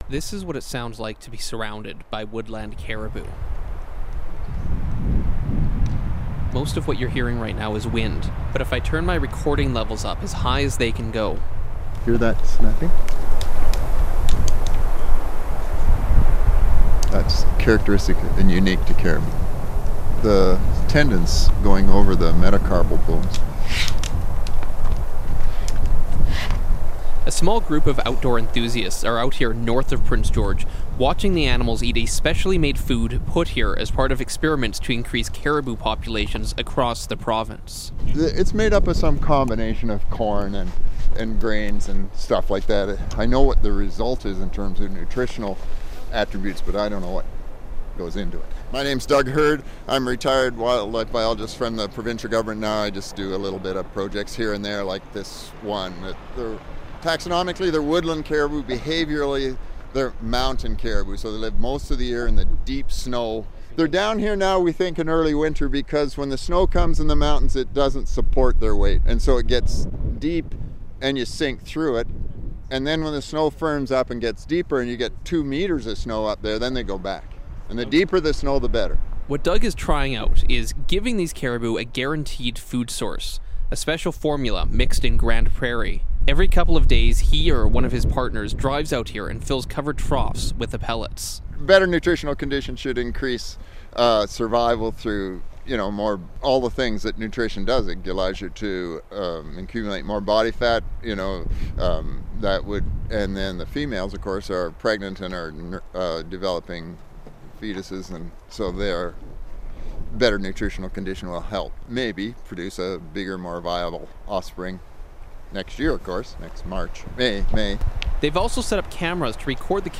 Listen to the 'snaps' of woodland caribou north of Prince George